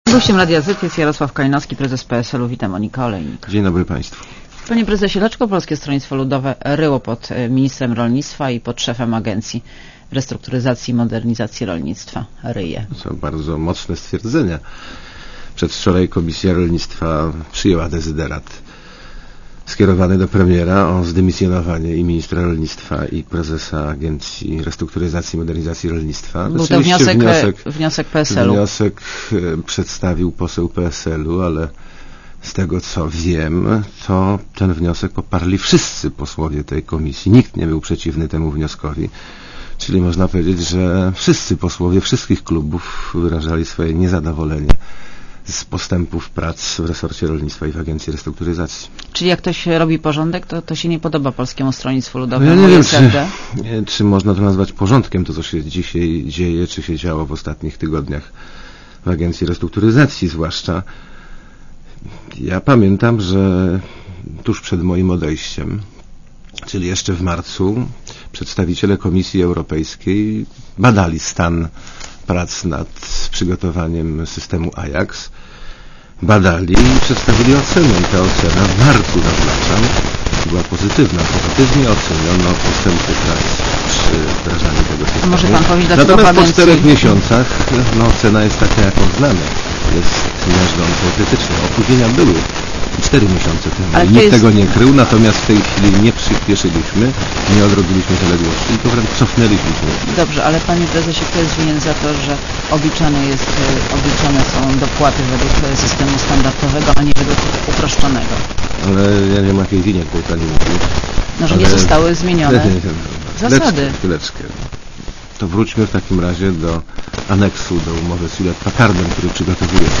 © (RadioZet) Posłuchaj wywiadu (2,9 MB) Panie Prezesie, dlaczego Polskie Stronnictwo Ludowe ryje pod ministrem rolnictwa i pod szefem Agencji Restrukturyzacji i Modernizacji Rolnictwa?